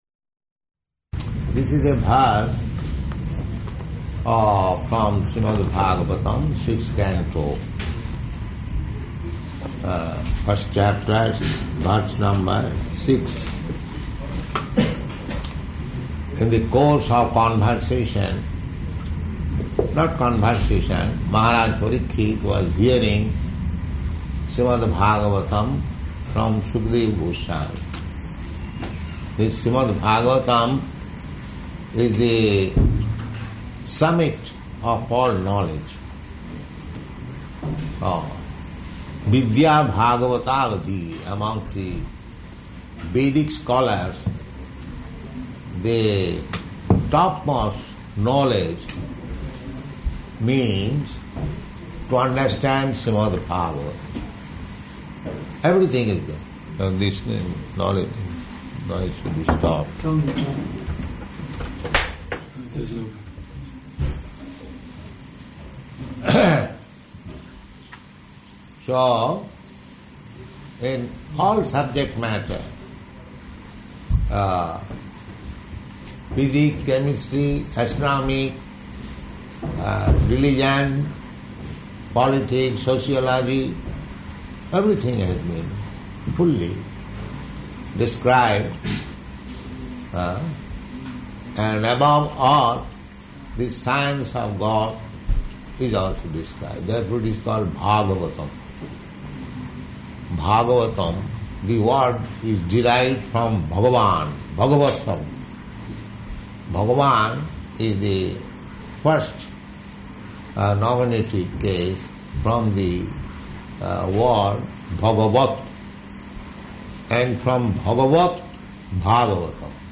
Location: Sydney